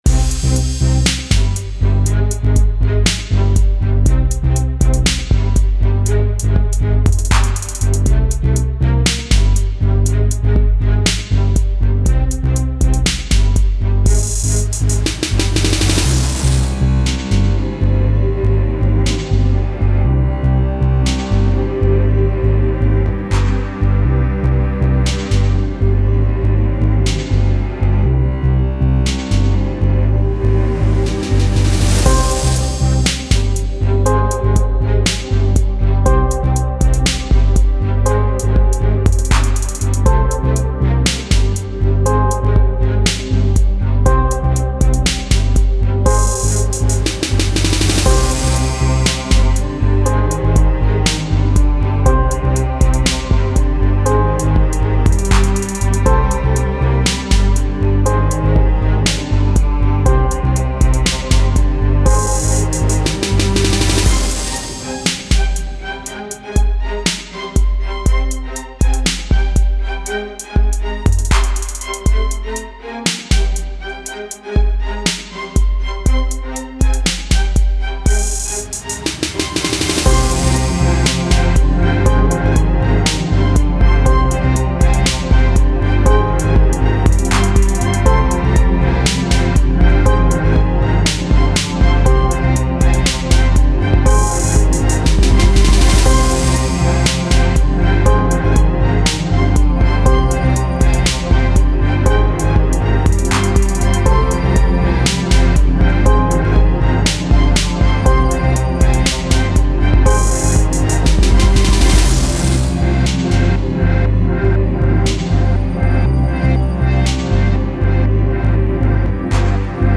Style Style EDM/Electronic, Hip-Hop
Mood Mood Cool, Relaxed
Featured Featured Bass, Cello, Drums +2 more
BPM BPM 60